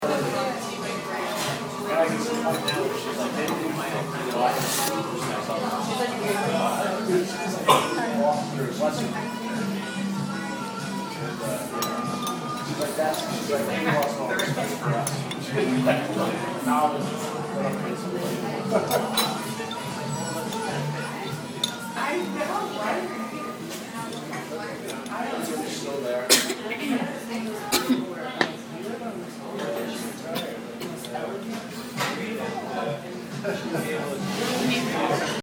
Cafe – Hofstra Drama 20 – Sound for the Theatre
Identify the location: A very quaint coffee shop near Union Square in the city.
Identify the sounds: Indistinct grumble of various conversations, the clattering of a spoon in a bowl of soup (distinct), someone slurping their soup, background harmonica music, someone coughing (distinct).